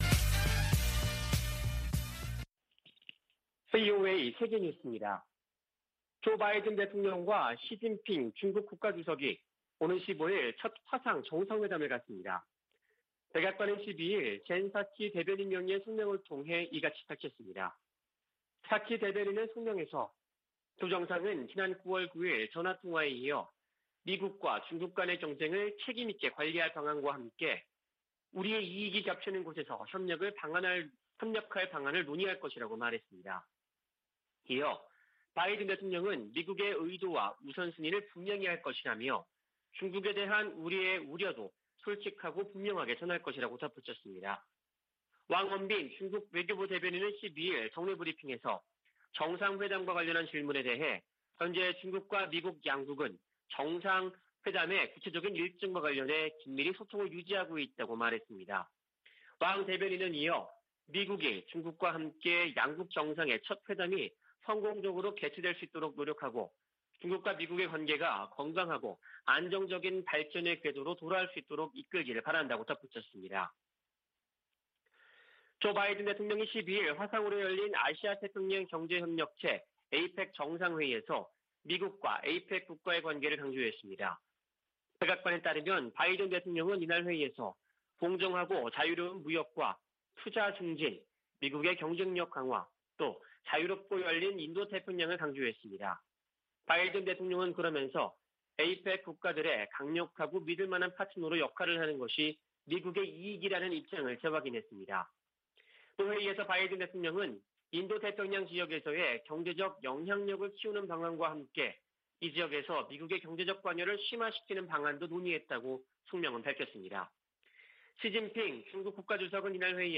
VOA 한국어 아침 뉴스 프로그램 '워싱턴 뉴스 광장' 2021년 11월 13일 방송입니다. 미국과 종전선언의 큰 원칙에 합의했다는 한국 외교부 장관의 발언이 나오면서 실제 성사 여부에 관심이 쏠리고 있습니다. 북한이 핵실험을 유예하고 있지만 미사일 탐지 회피 역량 개발에 집중하고 있다고 미국 유력 신문이 보도했습니다. 미국 정부가 캄보디아와 연관된 미국 기업들에 대한 주의보를 발령하면서, 북한의 현지 불법 활동에 대해서도 주의를 당부했습니다.